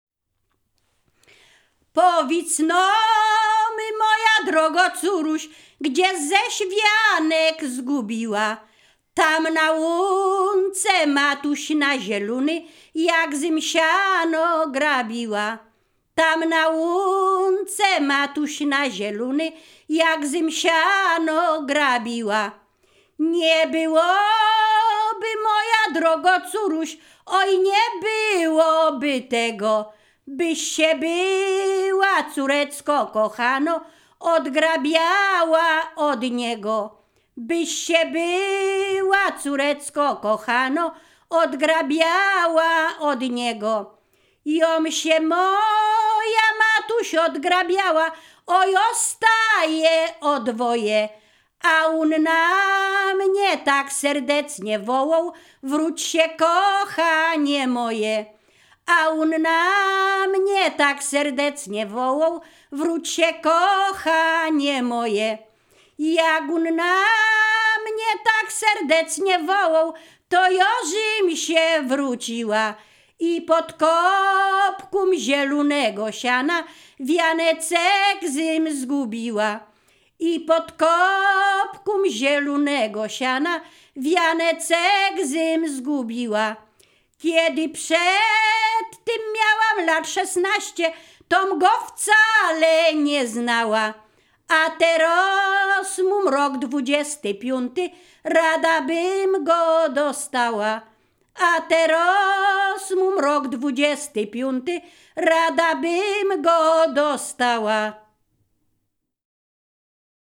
Ziemia Radomska
liryczne miłosne żartobliwe